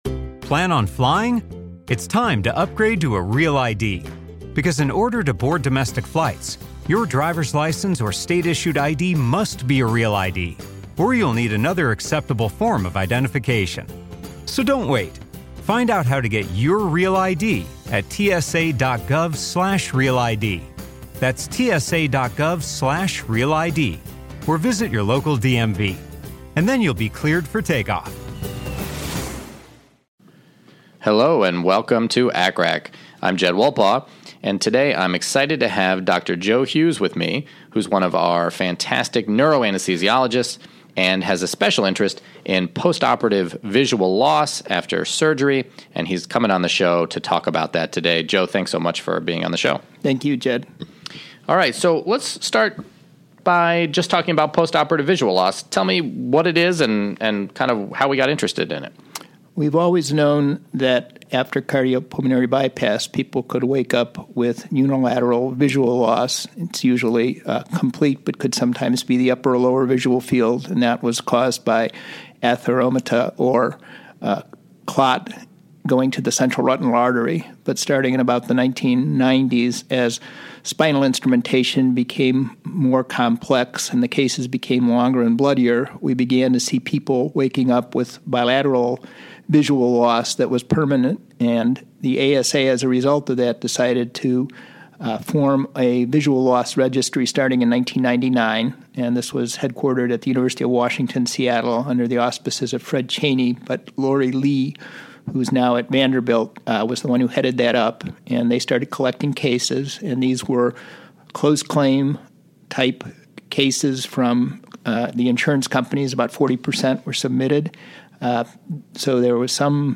In this episode I interview